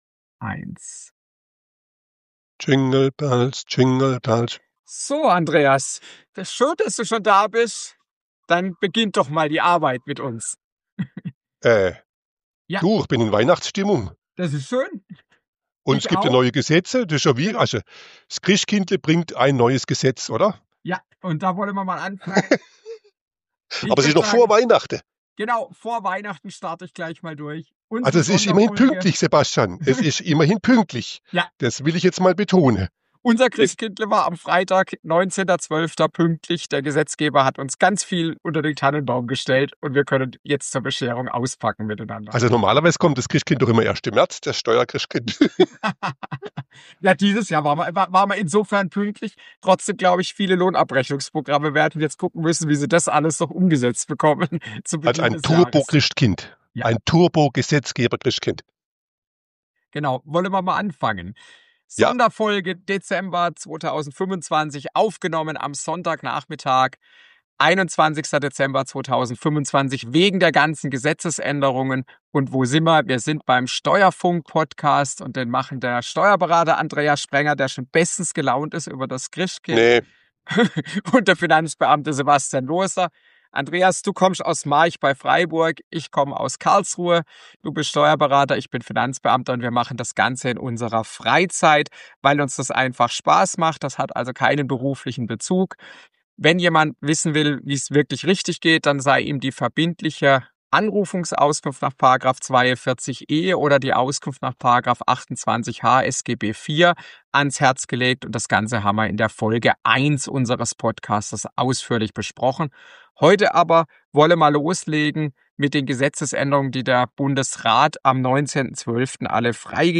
unterhalten sich in ihrer Freizeit über lohnsteuerliche und sozialversicherungsrechtliche Themen.